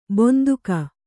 ♪ bonduka